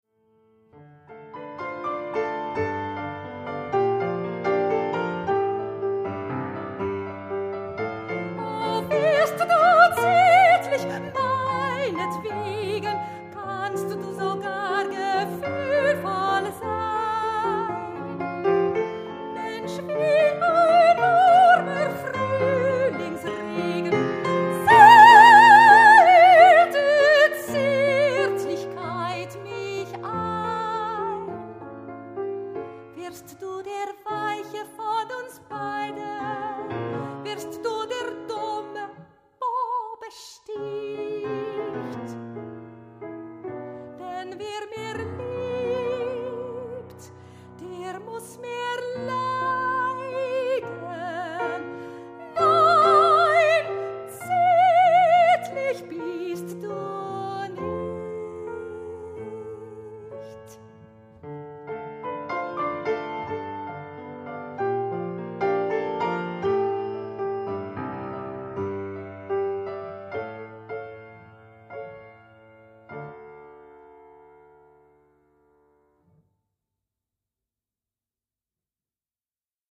Liedkunst